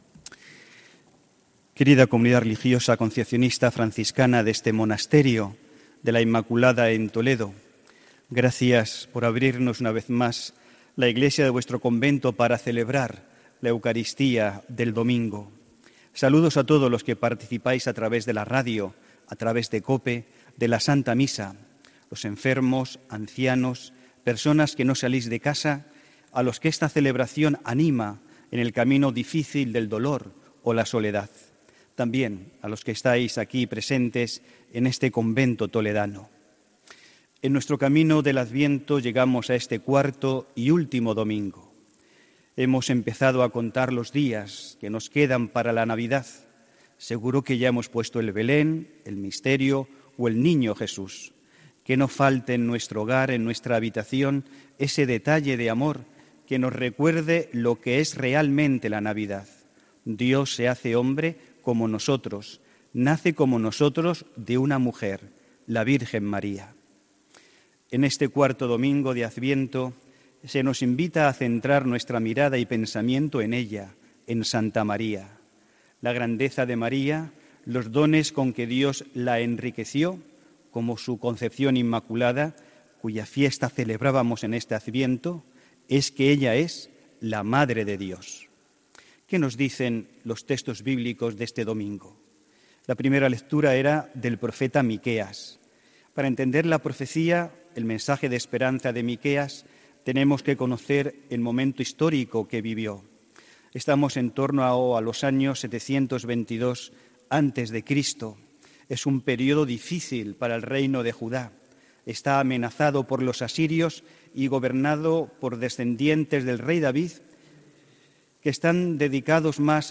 HOMILÍA 23 DICIEMBRE 2018